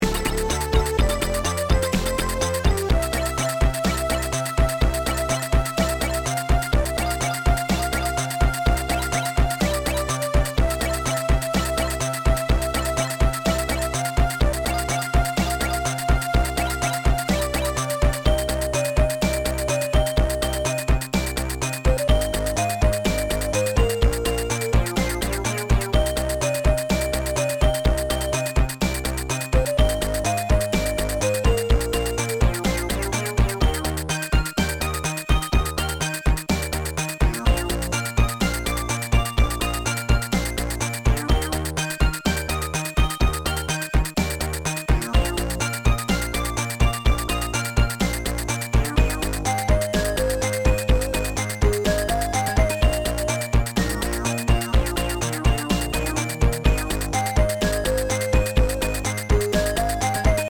File 1 - This is the recording from the sound output directly (from motherboard).